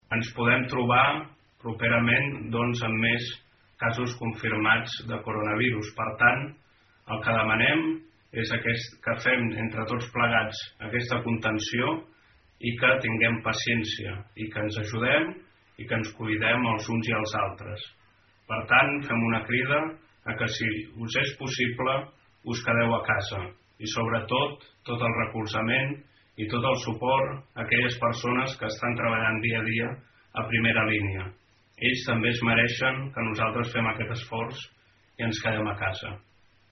En un vídeo adreçat als seus veïns, l’alcalde de Malgrat, Joan Mercader, confirma aquest primer cas i recorda als ciutadans que la situació que vivim no és cap broma i demana als malgratencs que es facin cas a les autoritats.